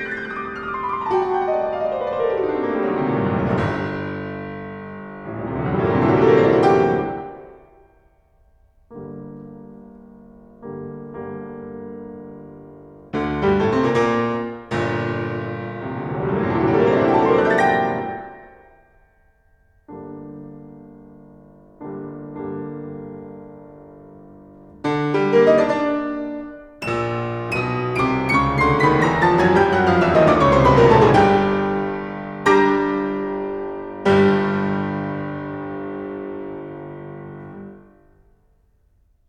Le intervallo es dissonante, un quarta augmentate.